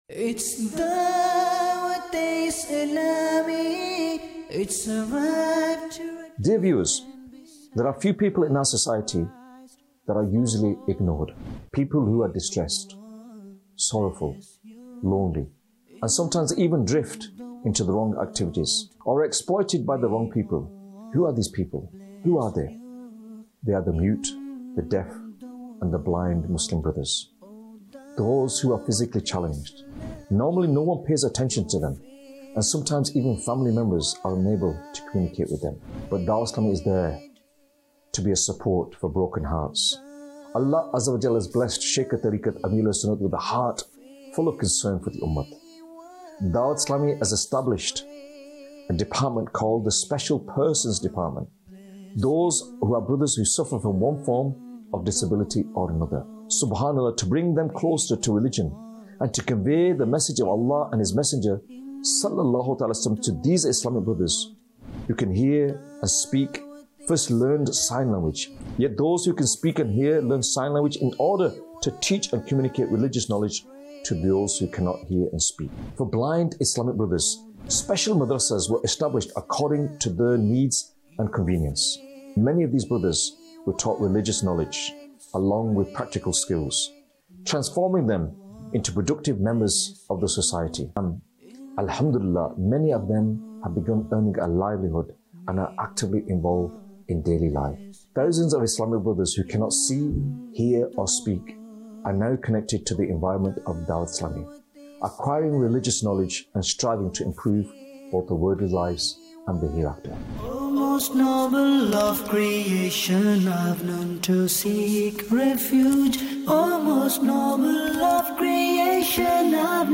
Documentary 2026